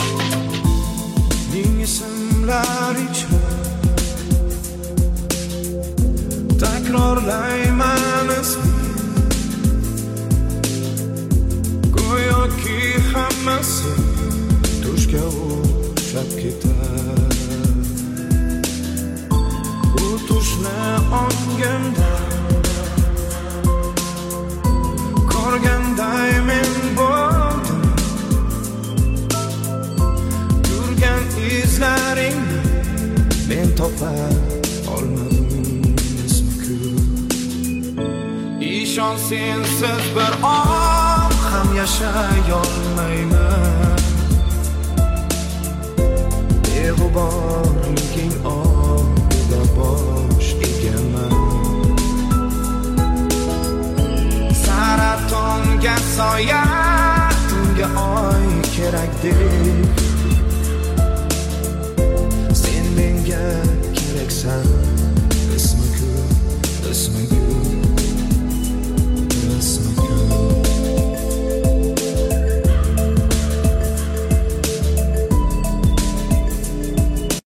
Жанр: Казахские / Узбекские